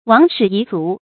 亡矢遺鏃 注音： ㄨㄤˊ ㄕㄧˇ ㄧˊ ㄗㄨˊ 讀音讀法： 意思解釋： 損失箭和箭頭。比喻軍事上的細微損失。